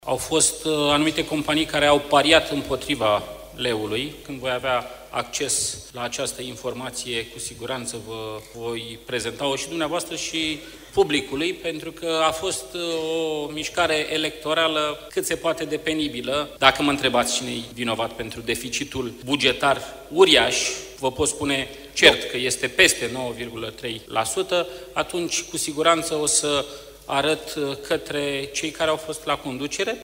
George Simion și Nicușor Dan, cei doi candidați la președinție din turul doi al prezidențialelor, declarații la Congresul Blocului Național Sindical.